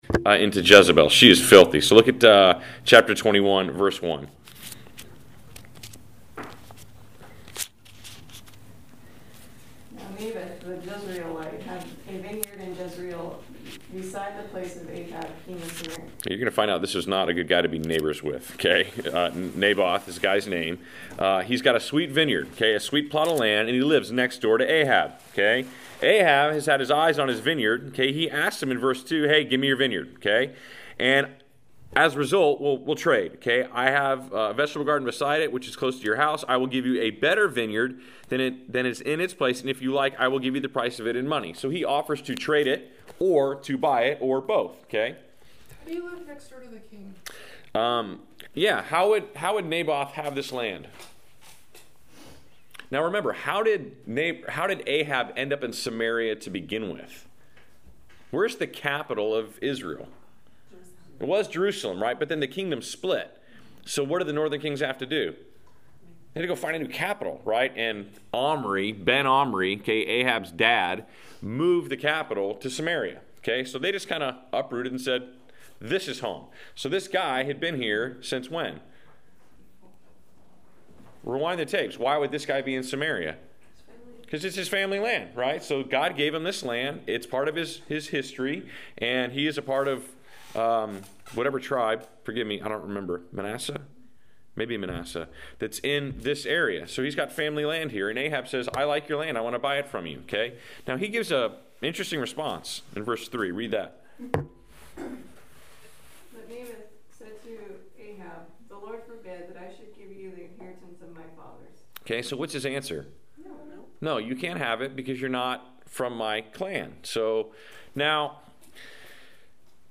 Class Session Audio